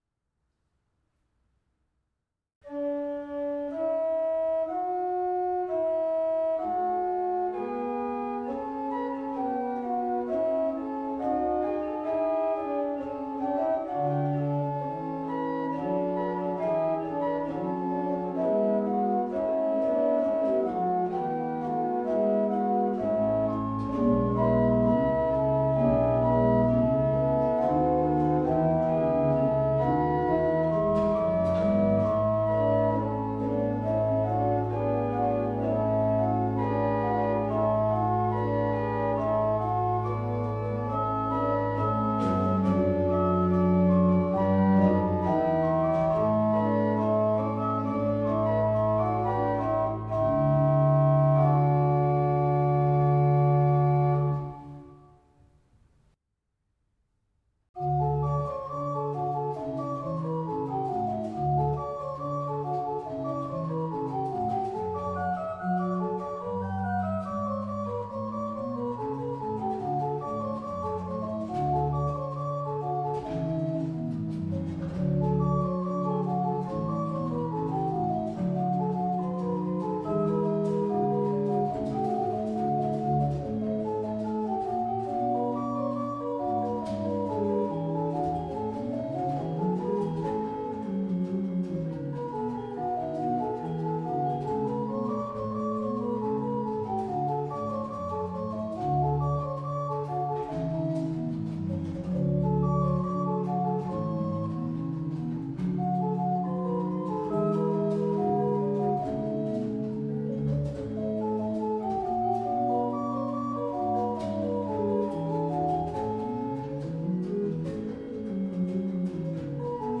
Nunspeet N.H.Dorpskerk